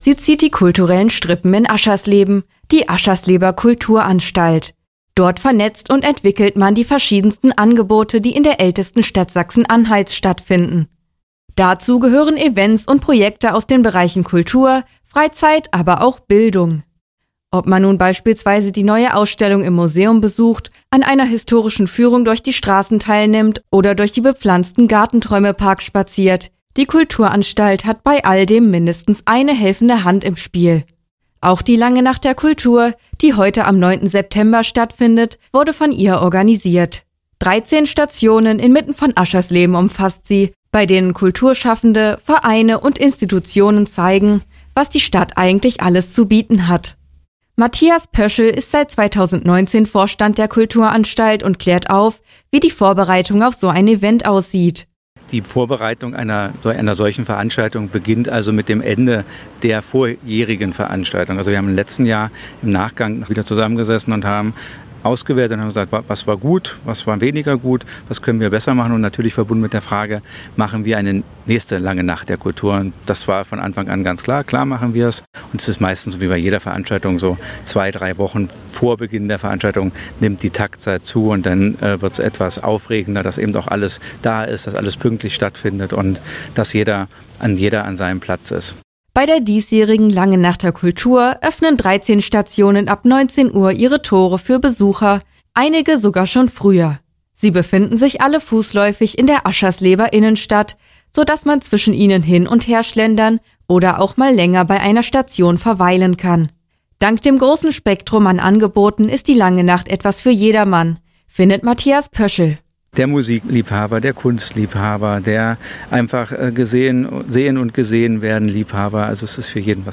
Hörbeitrag vom 9. September 2023